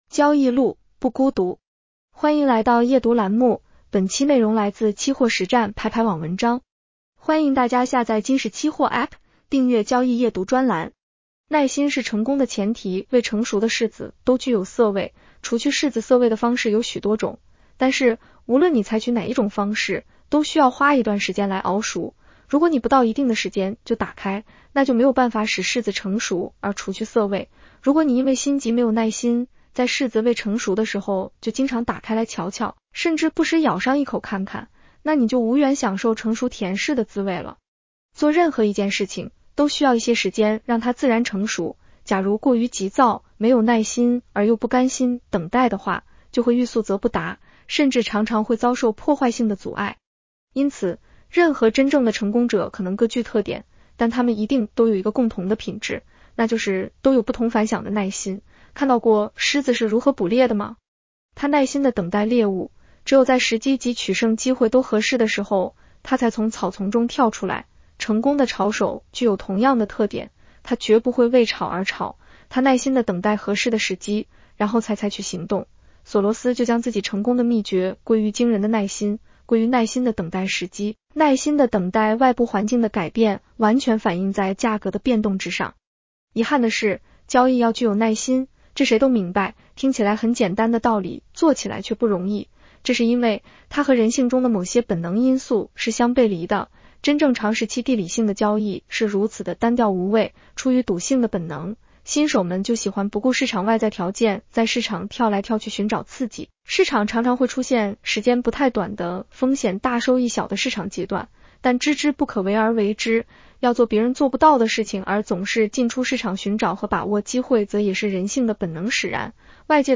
女声普通话版 下载mp3 耐心是成功的前提 未成熟的柿子都具有涩味，除去柿子涩味的方式有许多种，但是，无论你采取哪一种方式，都需要花一段时间来熬熟，如果你不到一定的时间就打开，那就没有办法使柿子成熟而除去涩味，如果你因为心急没有耐心、在柿子未成熟的时候就经常打开来瞧瞧，甚至不时咬上一口看看，那你就无缘享受成熟甜柿的滋味了。